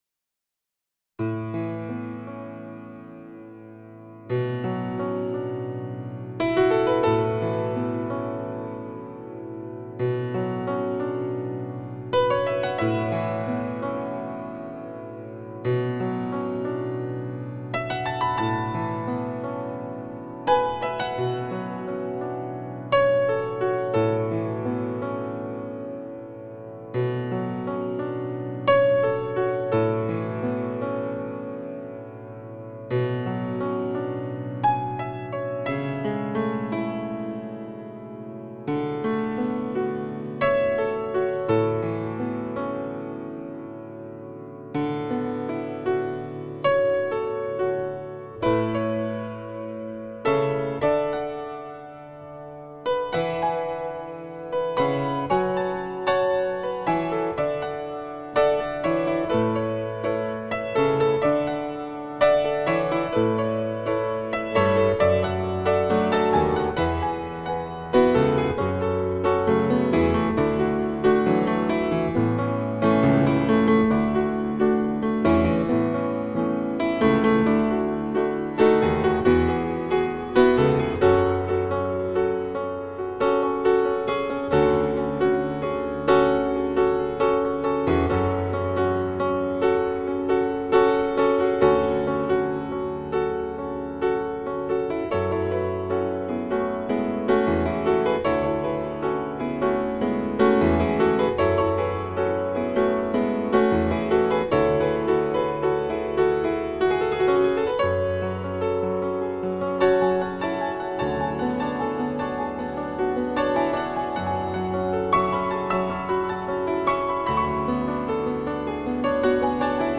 行云流水般的琴声宛若天籁，有着在草原的微风里极目远眺的惬意。